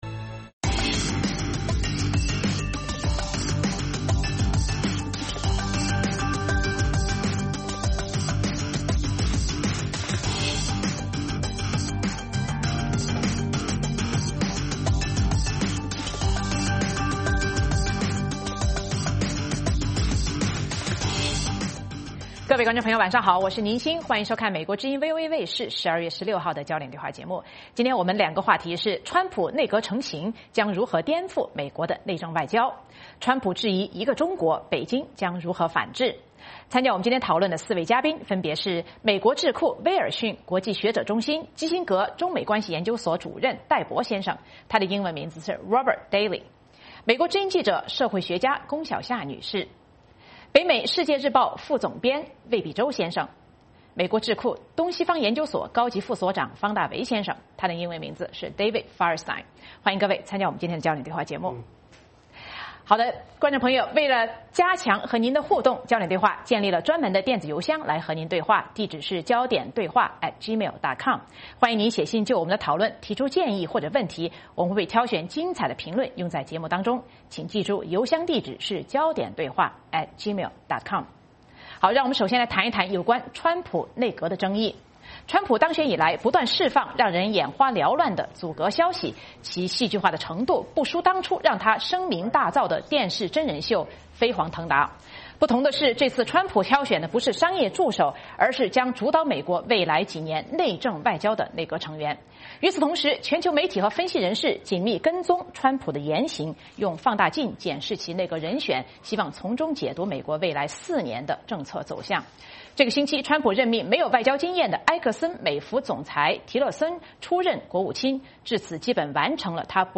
《焦点对话》节目追踪国际大事、聚焦时事热点。邀请多位嘉宾对新闻事件进行分析、解读和评论。或针锋相对、或侃侃而谈。